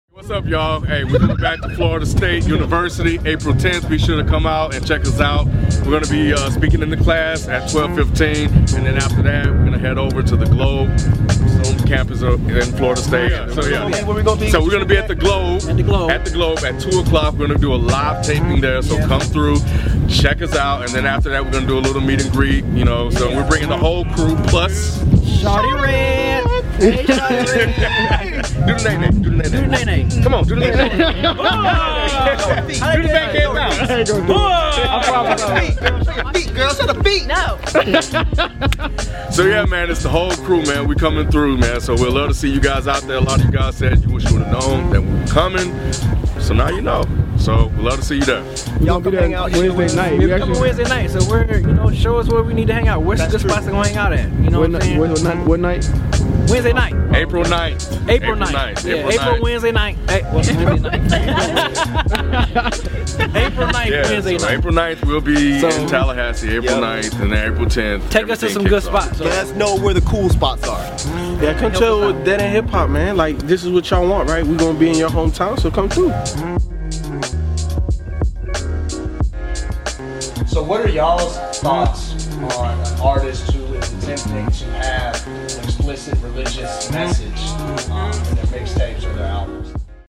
DEHH Live Taping at FSU April 10th